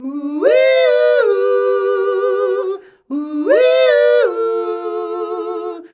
UUIIUUHUU.wav